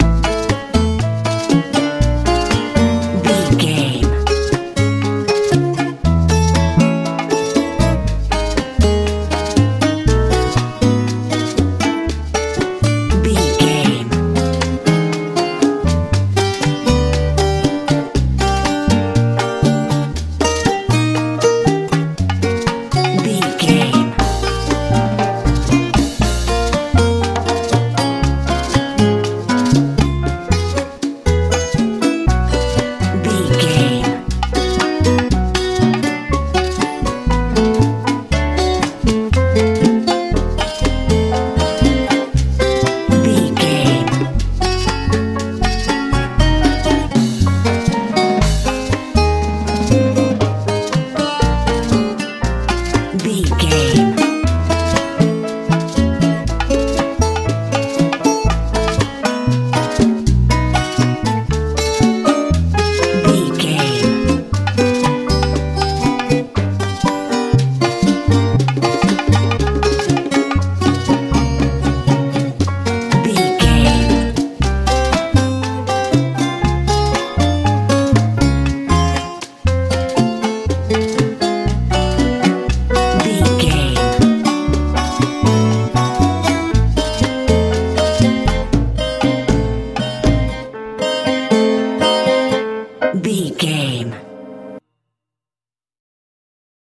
latin feel
Ionian/Major
happy
piano
acoustic guitar
bass guitar
drums
calm